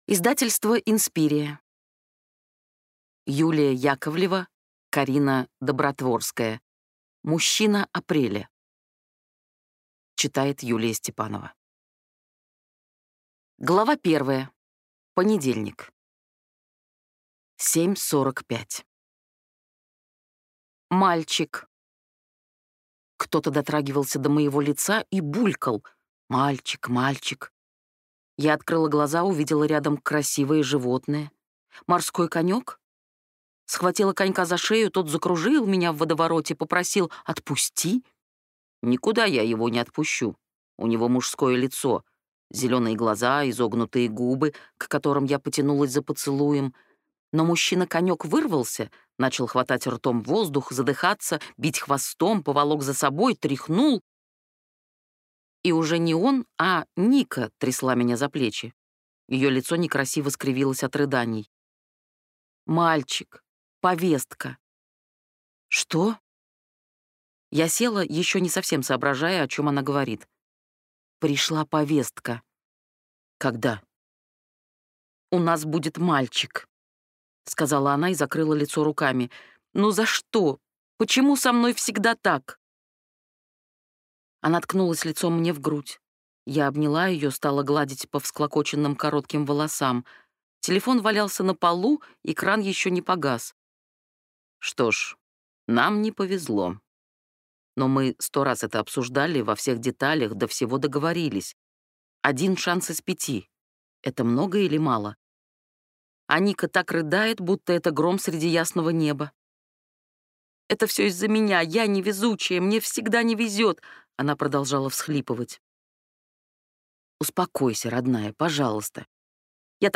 Aудиокнига Мужчина апреля